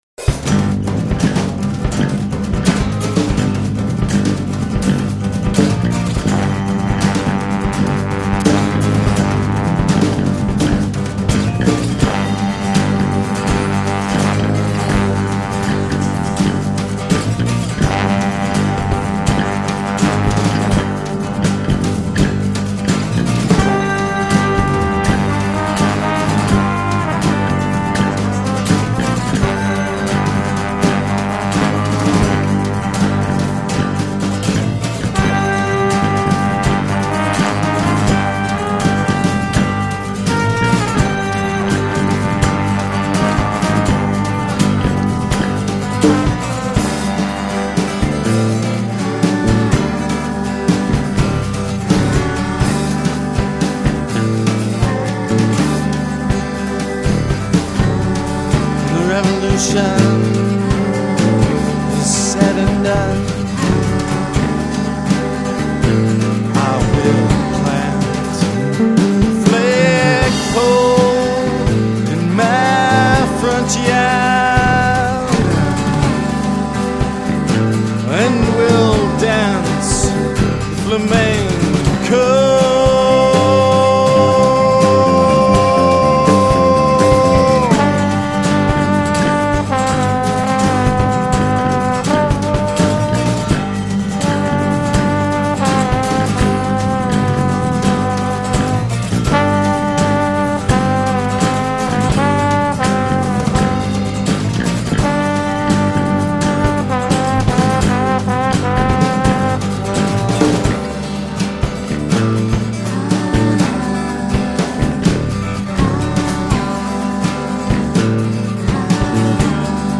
flamenco.mp3